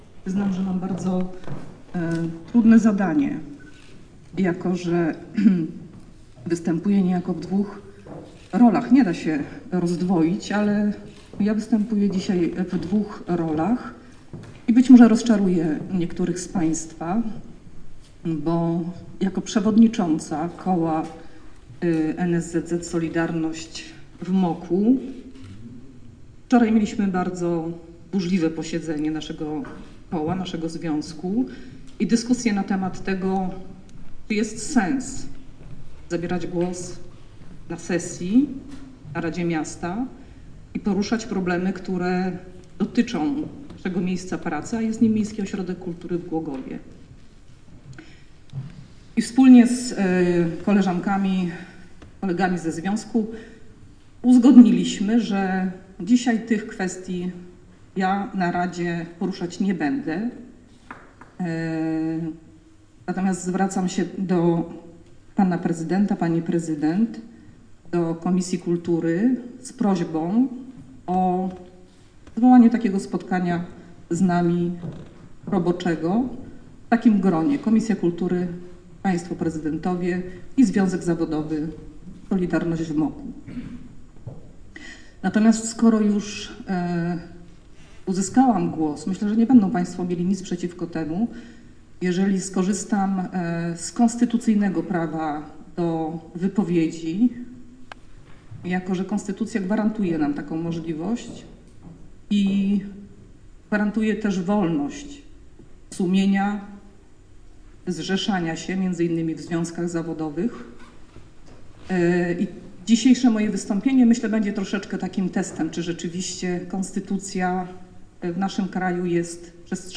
Mówiła o mobbingu, głos się jej łamał, sala milczała